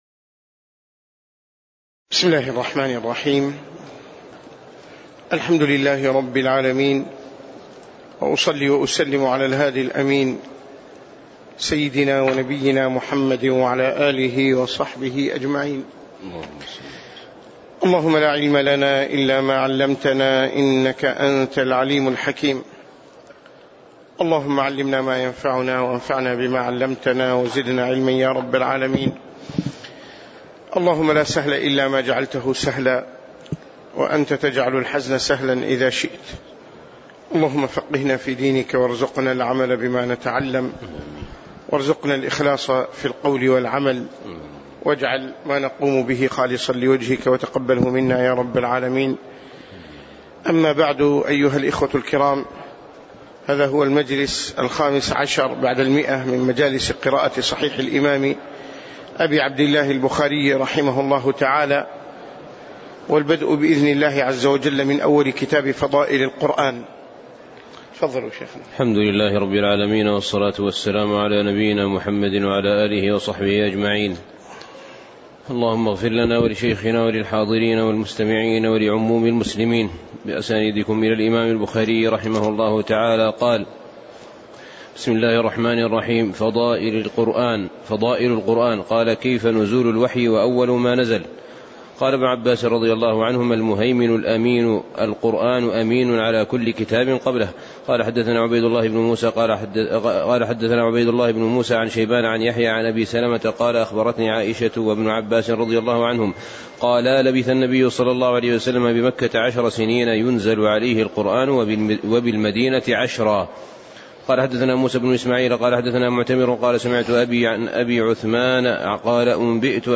تاريخ النشر ١١ شعبان ١٤٣٨ هـ المكان: المسجد النبوي الشيخ